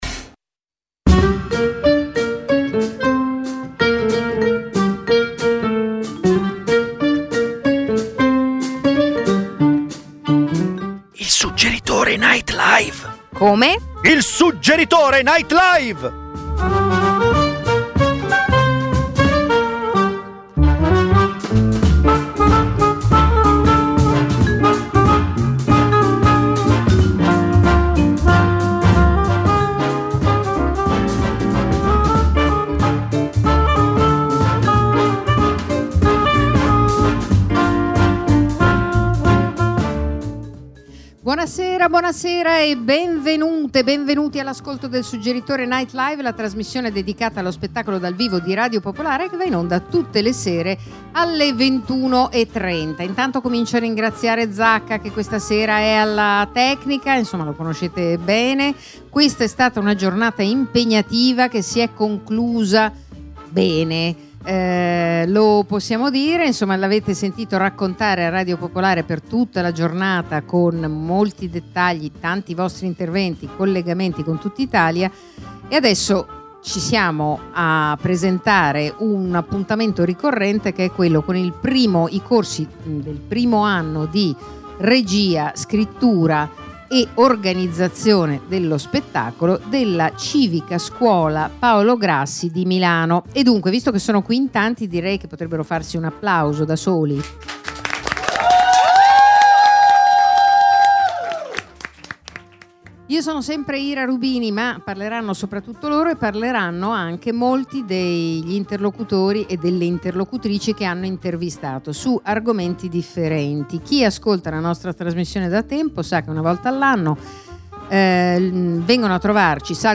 Il Suggeritore Night Live, ogni lunedì dalle 21:30 alle 22:30 dall’Auditorium Demetrio Stratos, è un night talk-show con ospiti dello spettacolo...
Gli ascoltatori possono partecipare come pubblico in studio a partire dalle 21.00. E spesso, il Suggeritore NL vi propone serate speciali di stand up, slam poetry, letture di drammaturgia contemporanea, imprò teatrale.